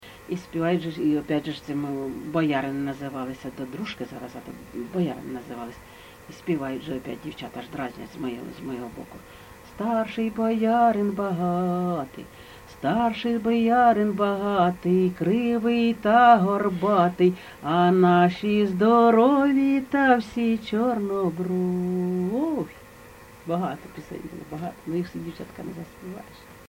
ЖанрВесільні
Місце записус. Серебрянка, Артемівський (Бахмутський) район, Донецька обл., Україна, Слобожанщина